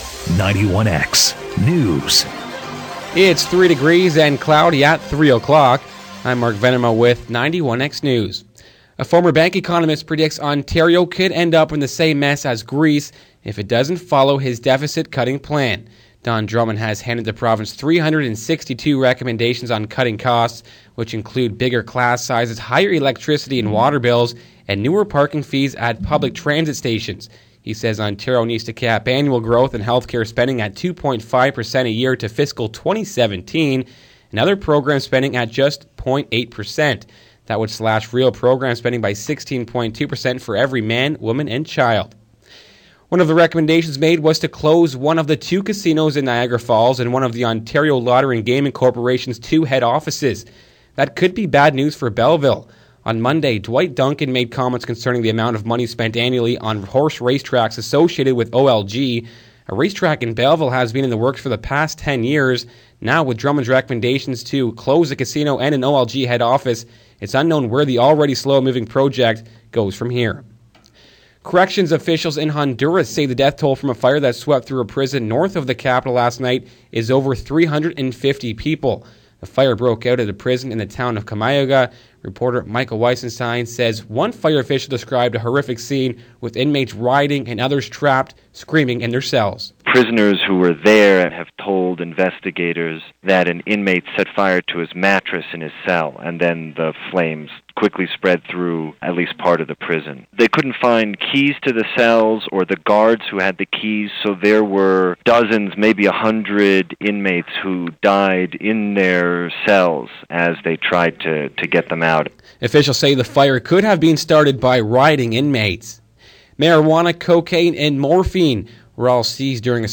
91X News In his report Don Drummond says Ontario could end up like Greece if there are no cutbacks.